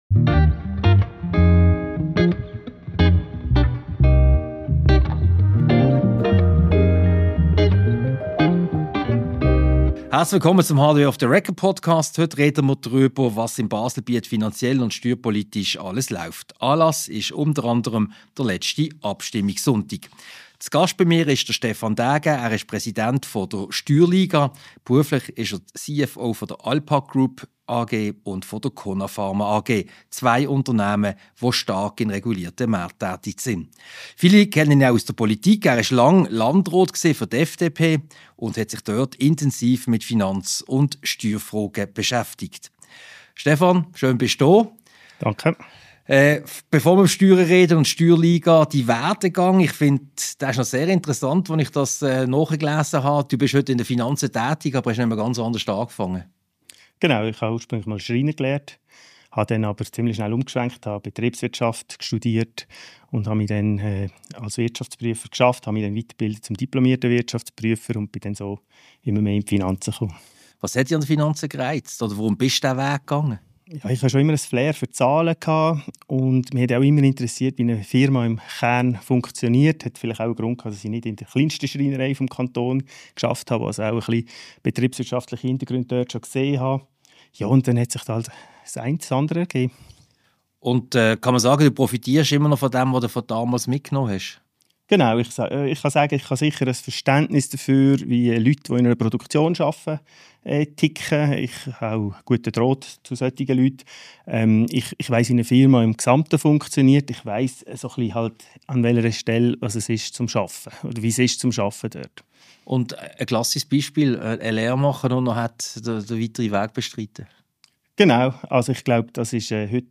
Ein Gespräch über die aktuelle Finanz- und Steuerpolitik im Kanton Baselland, über die längst fällige Reform der Einkommenssteuer und über die schwindende Wahrnehmung des Steuerwettbewerbs in der Politik.